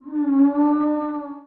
c_zombie_hit2.wav